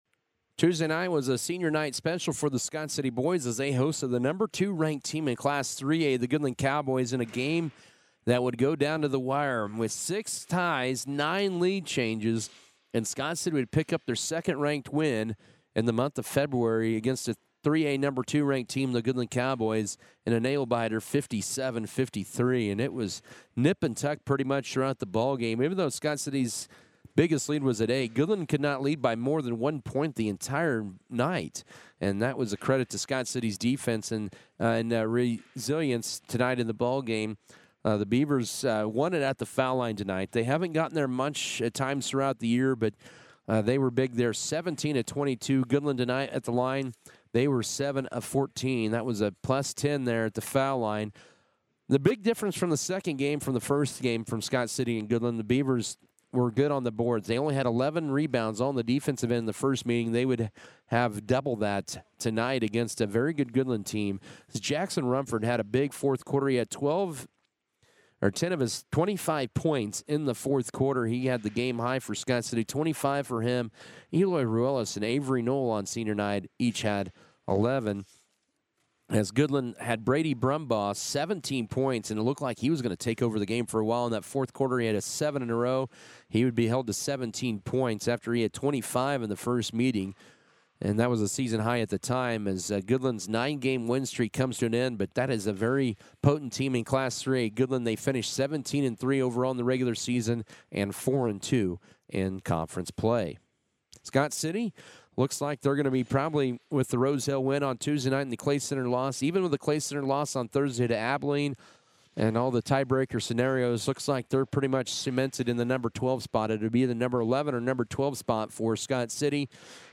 Boys Audio Recap